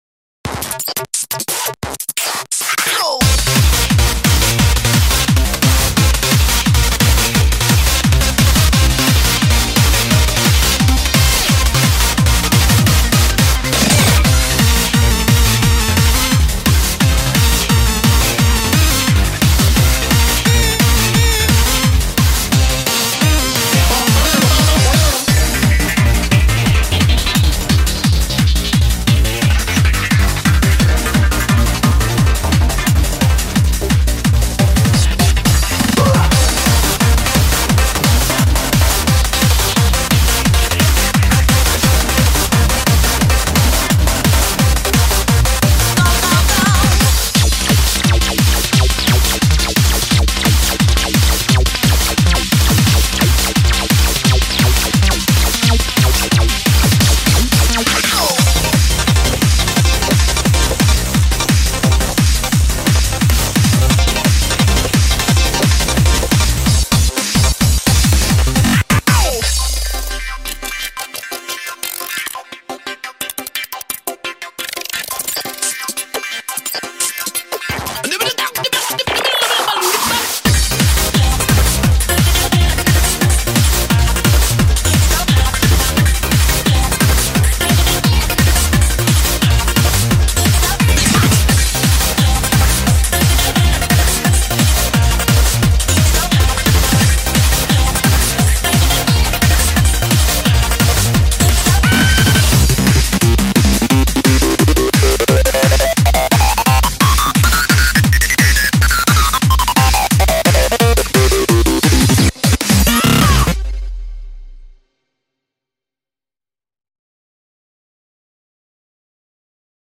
BPM174
Audio QualityPerfect (High Quality)
GENRE: TECHCORE